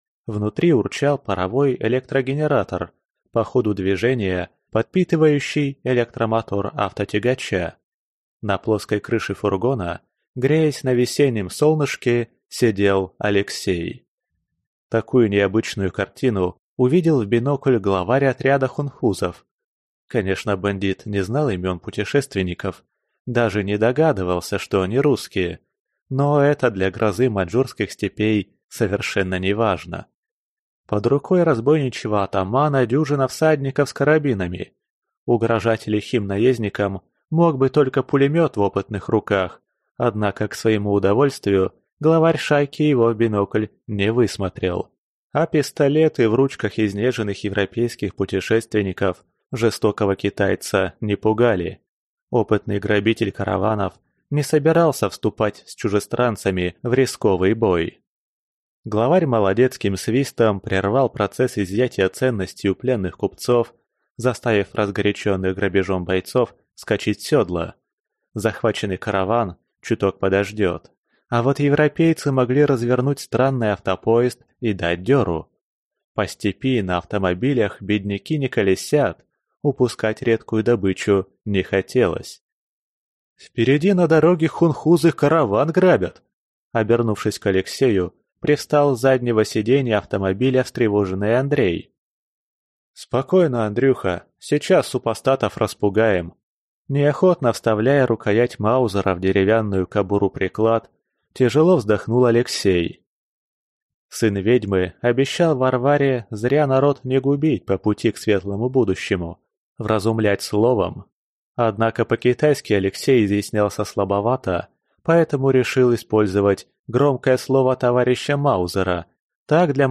Аудиокнига Пастырь | Библиотека аудиокниг